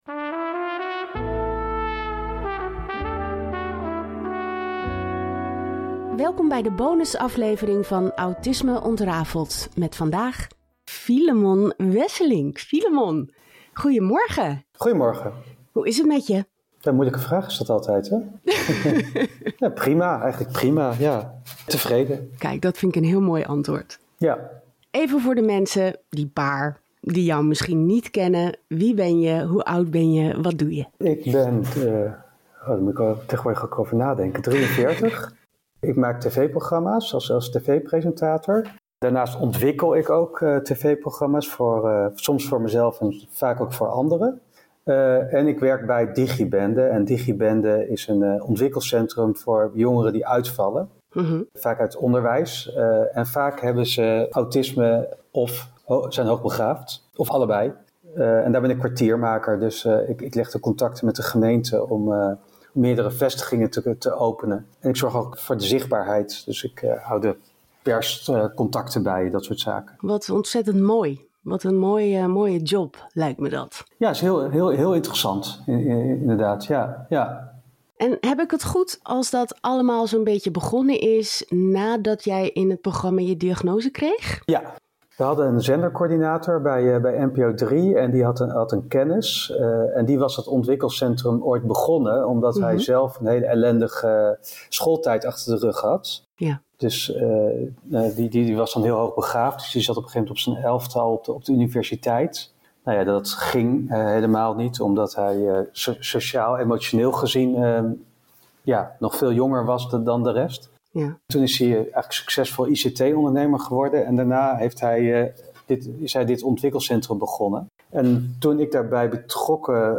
In deze tweede Bonus aflevering ga ik in gesprek met Filemon. Hij kreeg ooit in zijn tv programma de diagnose autisme, maar weet nu niet zo goed meer of autisme uberhaupt wel bestaat!